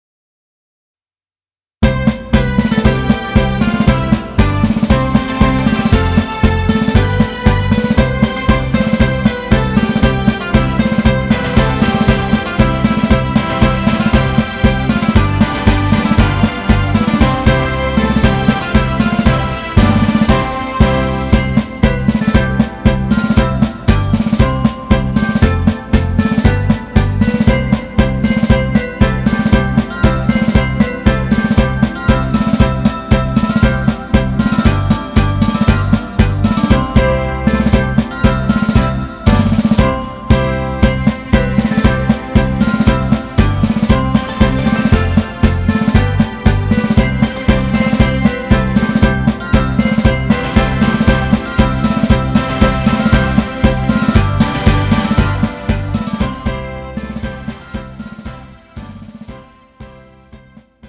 음정 C 키
장르 가요 구분 Pro MR